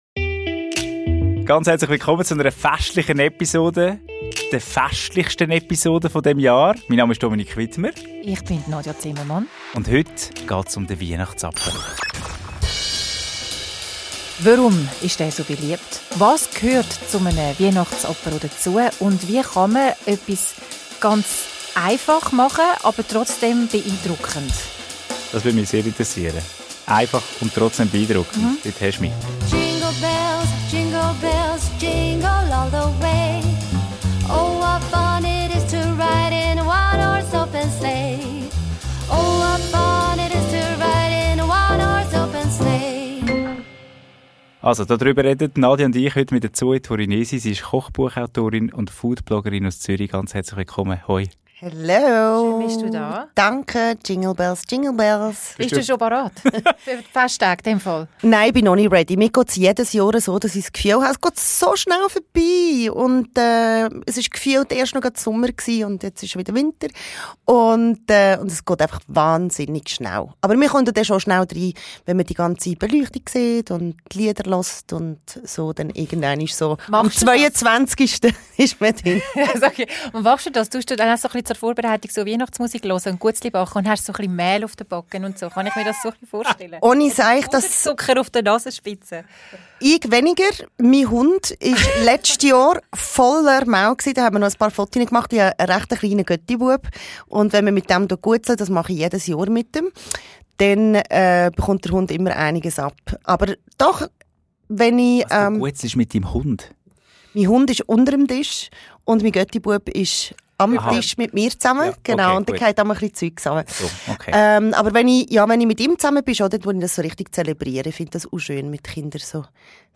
Ein Gespräch voller Ideen, Wärme und Inspiration für die schönste Zeit des Jahres.